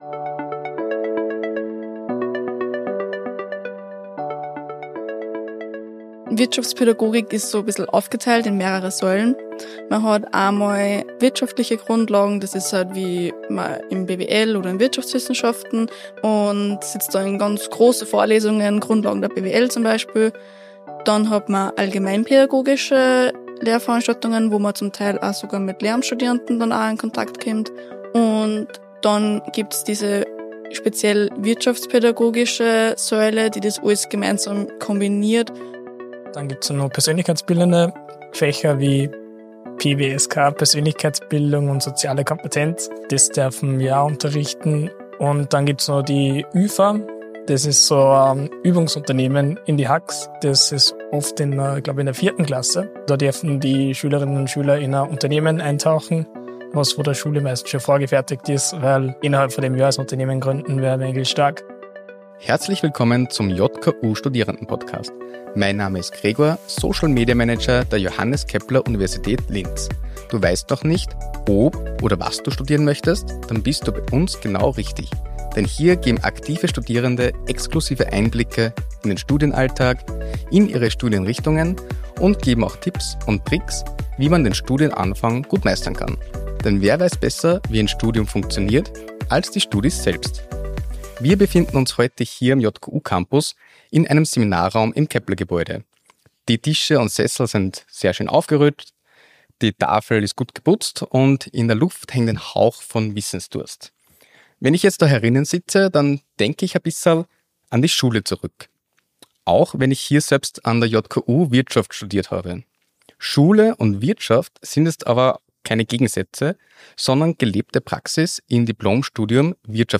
JKU Studierende im Gespräch Podcast